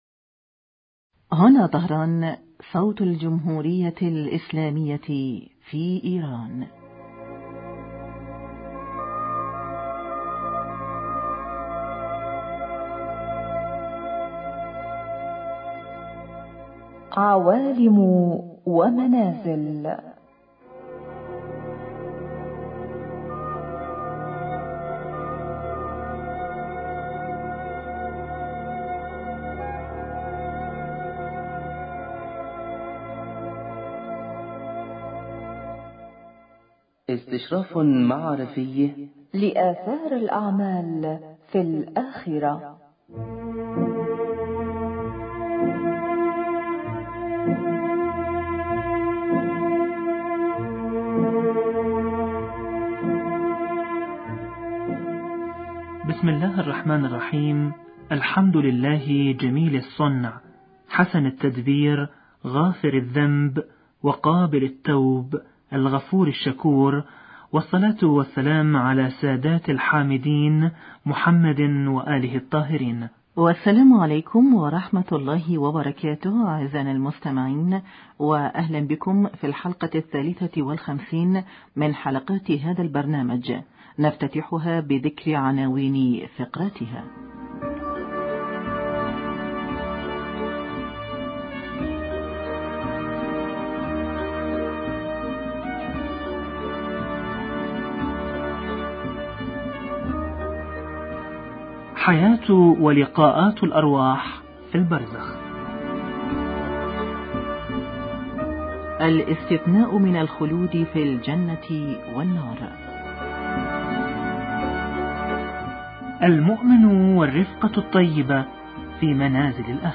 حياة ولقاءات الارواح في عالم البرزخ حوار